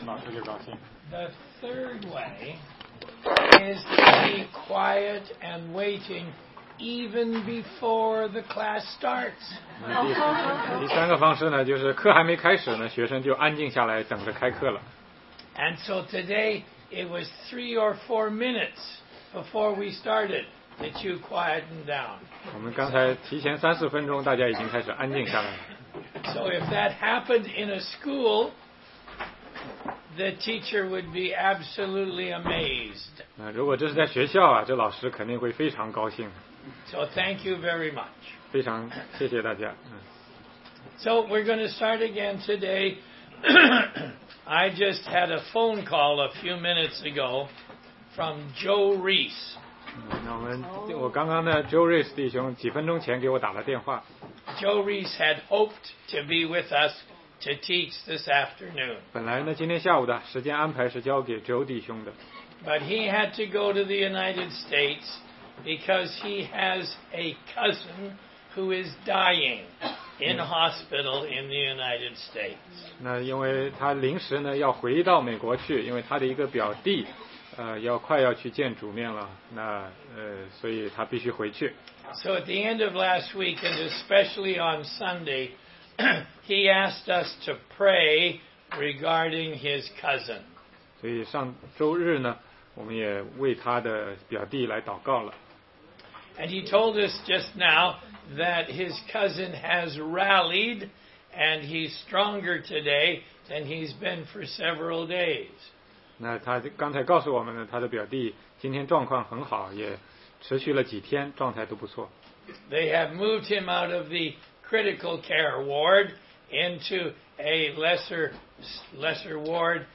16街讲道录音 - 哥林多前书10章6-14节：旷野的警告之四